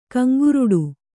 ♪ kaŋguruḍu